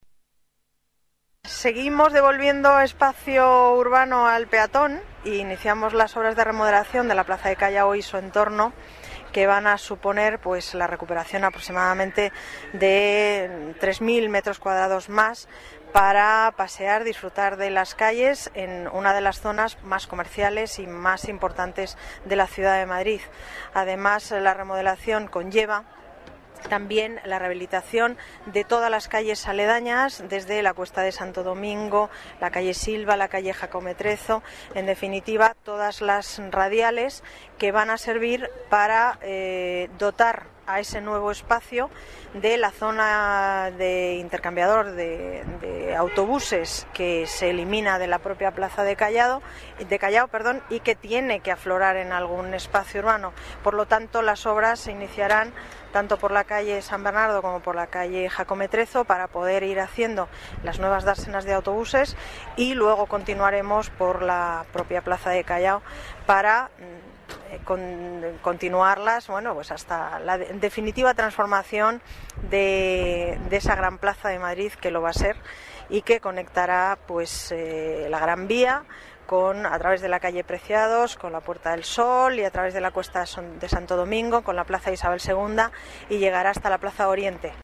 Nueva ventana:Declaraciones de la delegada de Obras, Paz González: Obras Callao